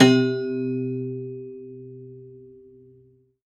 53p-pno06-C1.wav